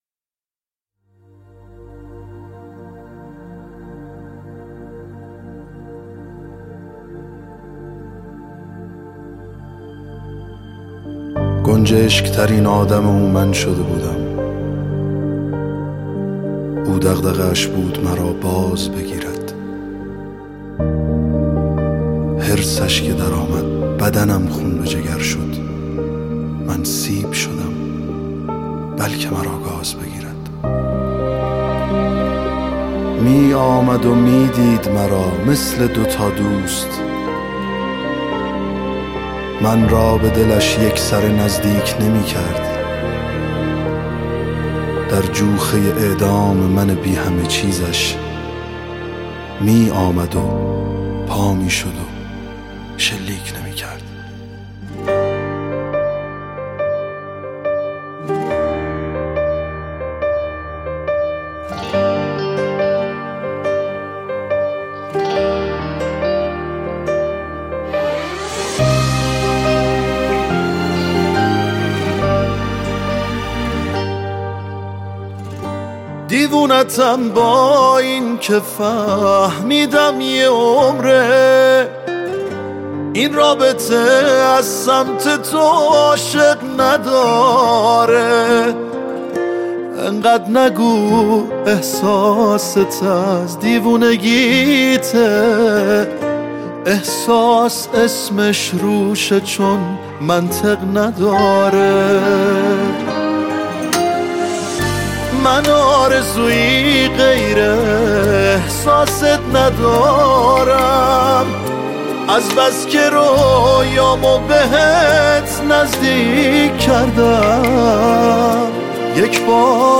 سبک : موسیقی پاپ